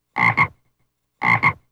frogsfx.659e4a1a.wav